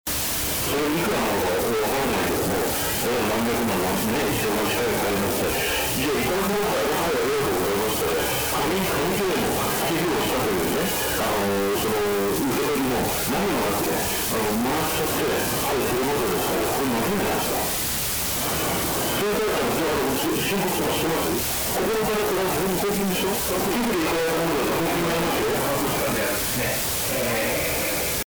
ノイズリダクション/ノイズゲート処理： 電源ノイズを取り除くために、ノイズリダクション/ノイズゲート処理を使用することができます。
3. 割れ音除去： 割れ音を取り除くためには、ディエンハードと呼ばれる手法が使用されます。
これらの技術を使用して、取材記者の会話を明確にすることができました。